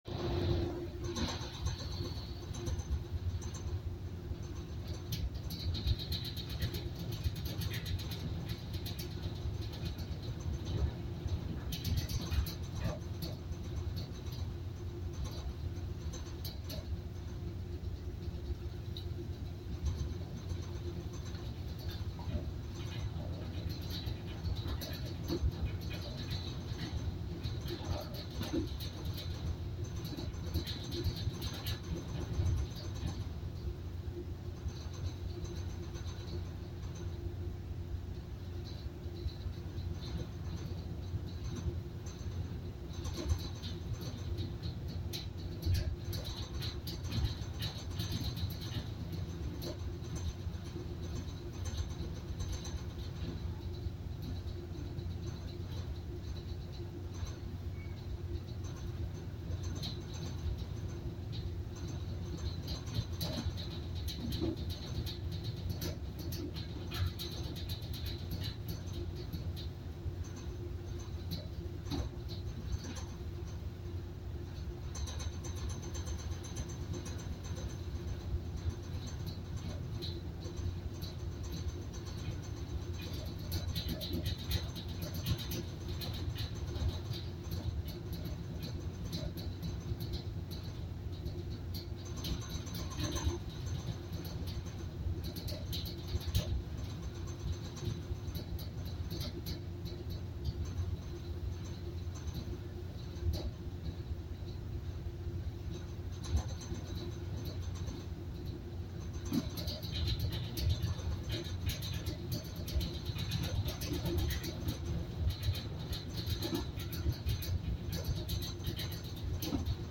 洗濯機の無料音源
洗濯機の音がノリノリだったので商用可で公開してみる。
フェリーの洗濯機